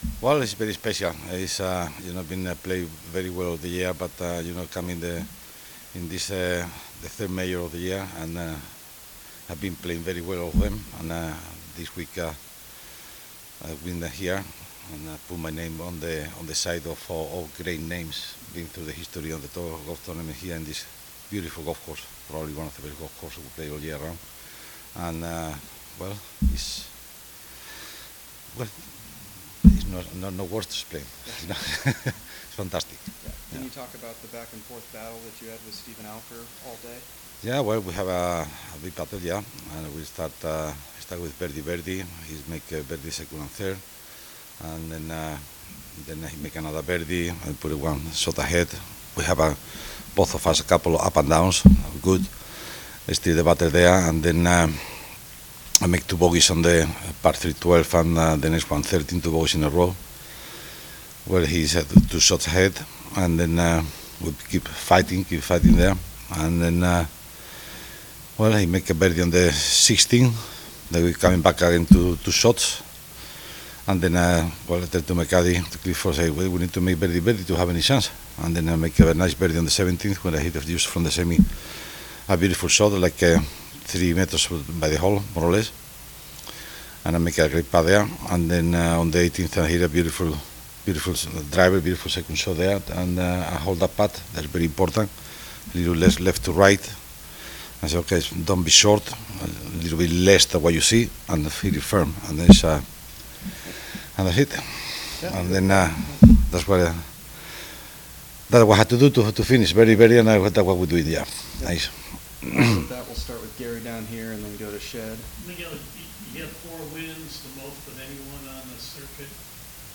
Kaulig Companies Championship, Final Round Press Conference, Sunday June 23, 2025: Miguel Angel Jimenez wins on second hole of Playoff; becomes first 4-time winner in 2025